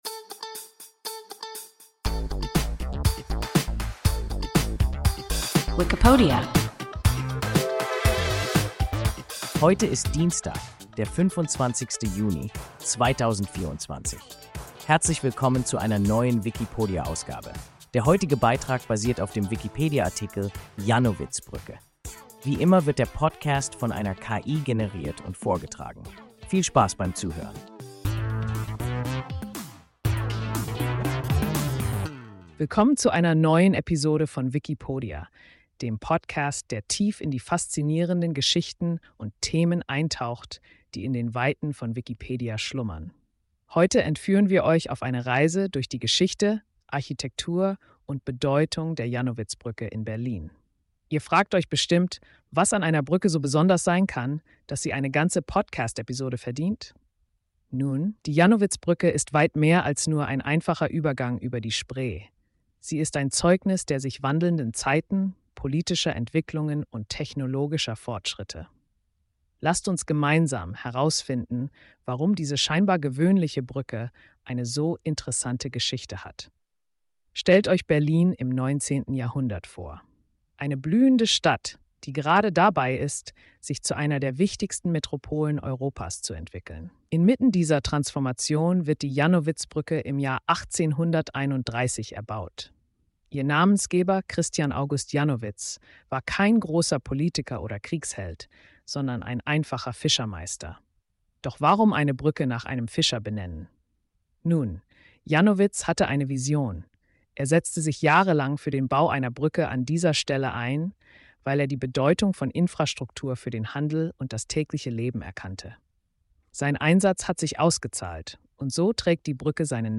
Jannowitzbrücke – WIKIPODIA – ein KI Podcast